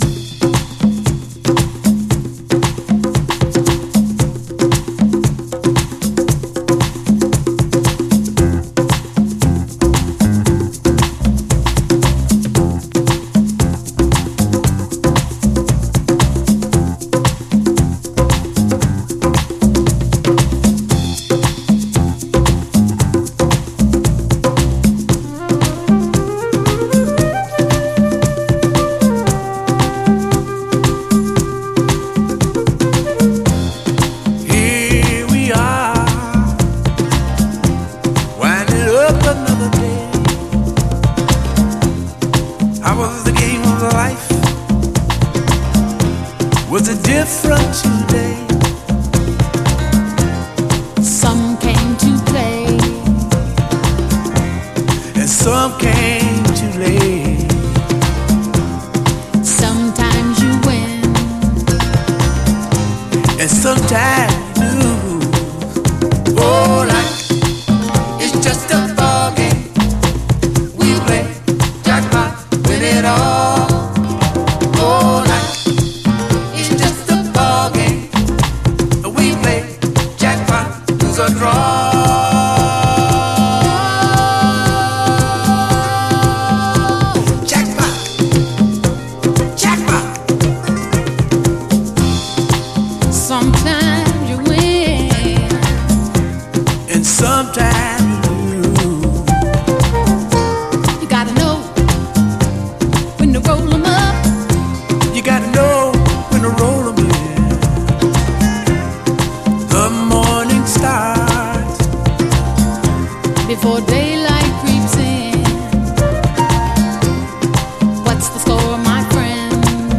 SOUL, 70's～ SOUL, DISCO
バレアリック・クラシック！瑞々しくオーガニックなグルーヴで包むメロウ・ステッパー！
ゆったりと瑞々しくオーガニックなグルーヴで包む優しいメロウ・ステッパー！